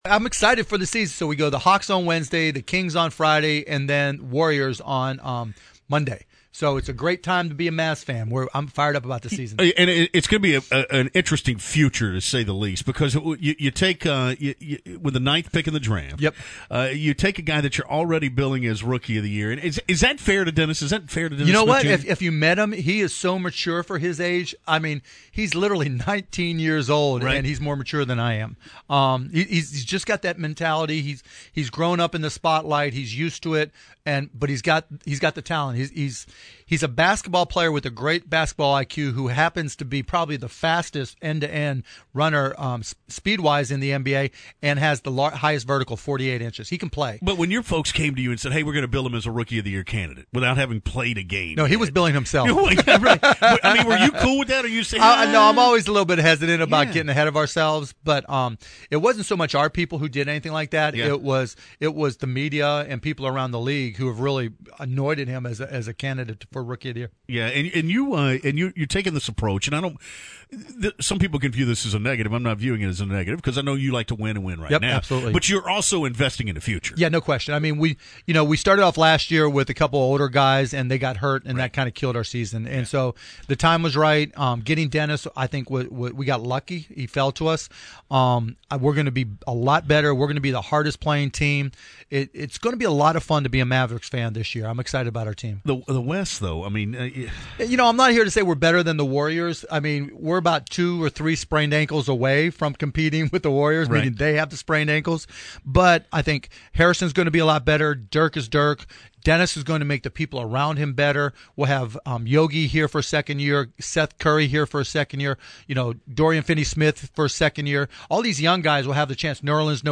DALLAS (WBAP/KLIF News) — Dallas Mavericks’ owner Mark Cuban joined WBAP and KLIF in studio Monday morning to discuss a variety of topics, including the job President Trump is doing, whether or not he will run for President in 2020, the national anthem kneeling controversy, and of course, the 2017-2018 Mavericks season.